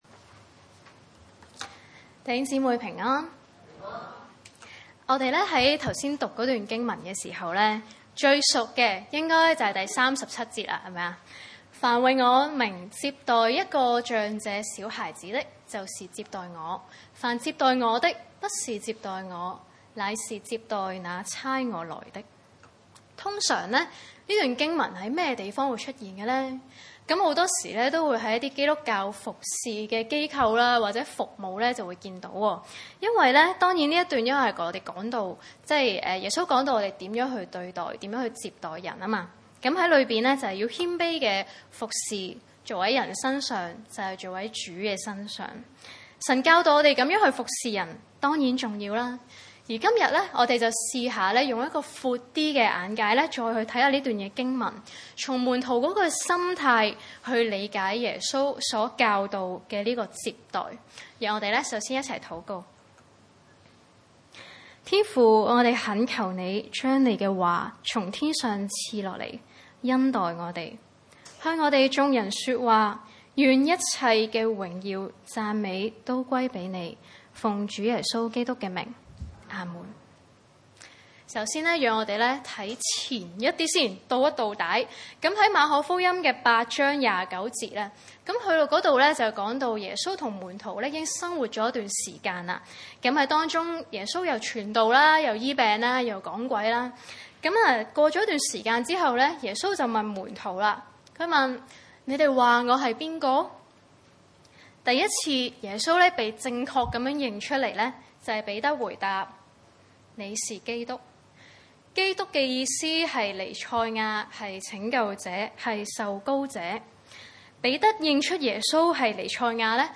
馬可福音9:30-37 崇拜類別: 主日午堂崇拜 30.